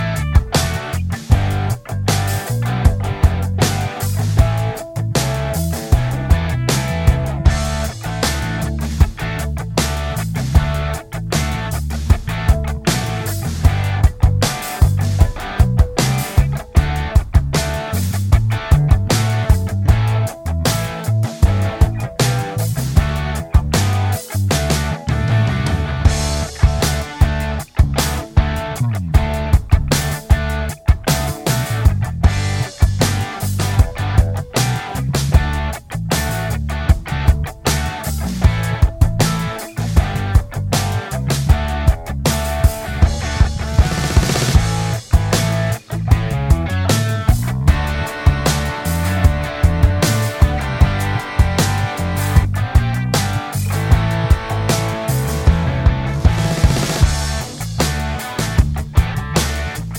Glam Rock